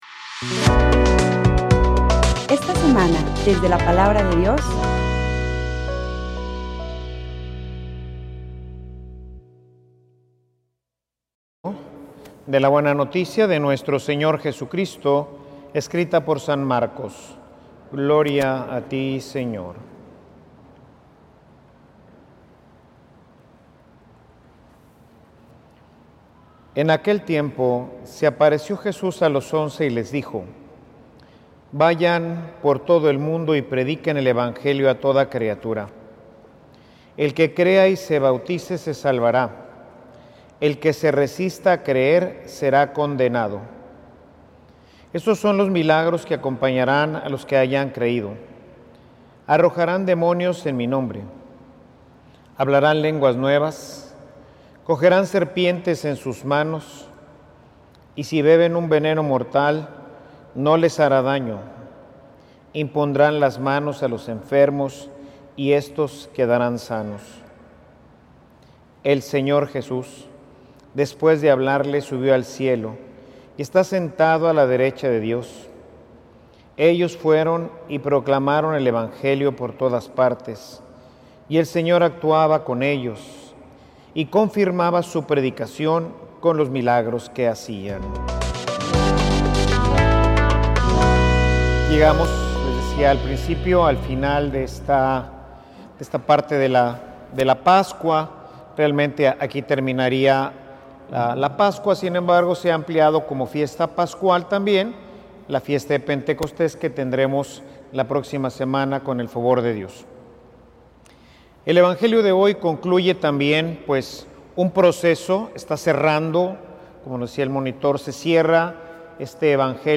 Homilia_mandato_y_ejecucion.mp3